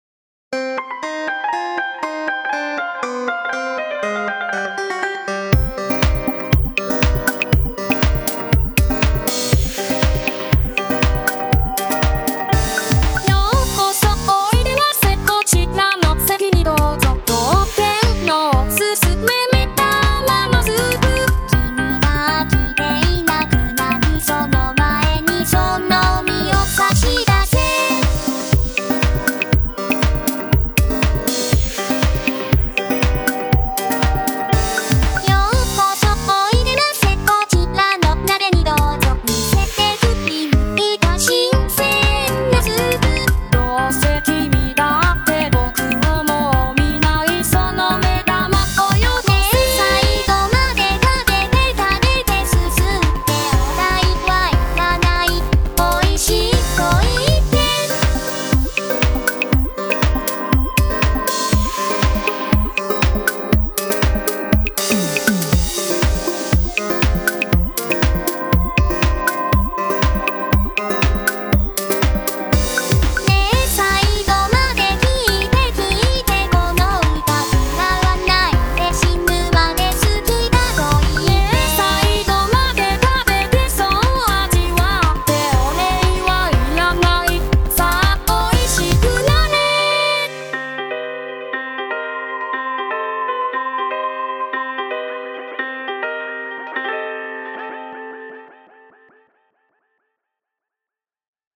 【VOCALOID】 mp3 DL ♪